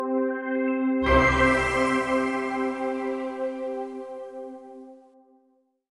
На этой странице собраны классические звуки Windows XP, которые стали символом эпохи.
Windows XP Media Center Edition (запуск)